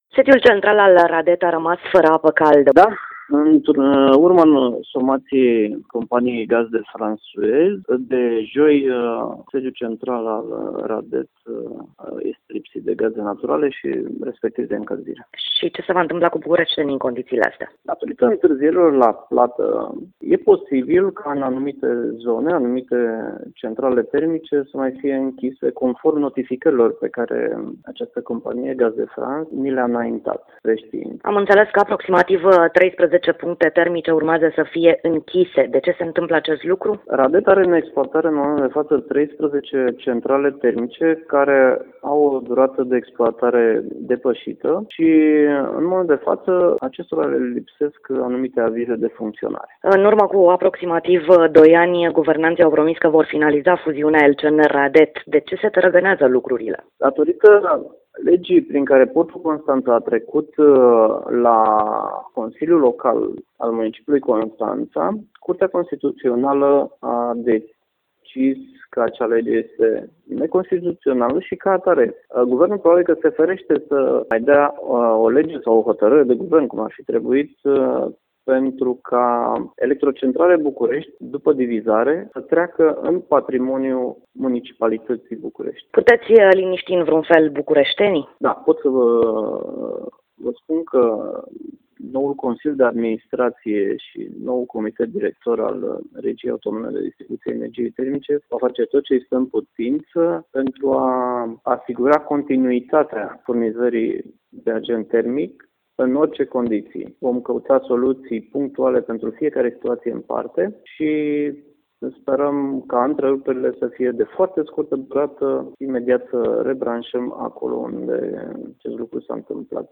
un interviu in exclusivitate